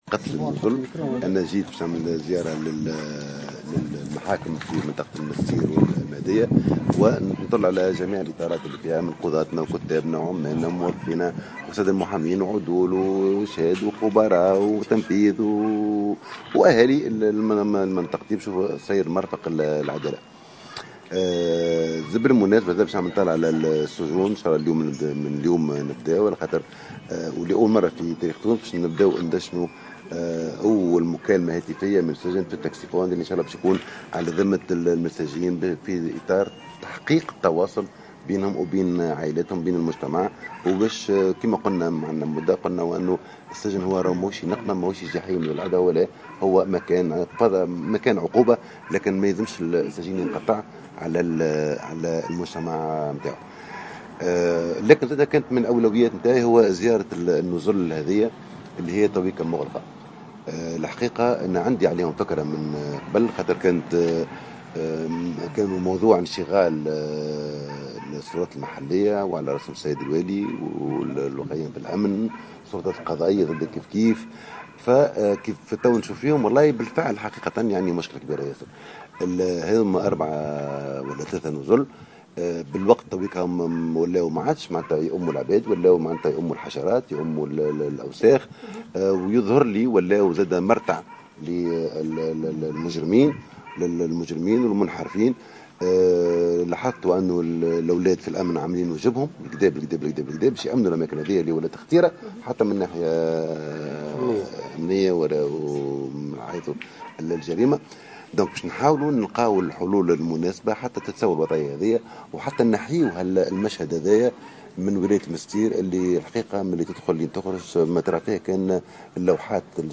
أكد وزير العدل عمر منصور في تصريح لمراسل الجوهرة اف ام على هامش زيارة أداها عشية...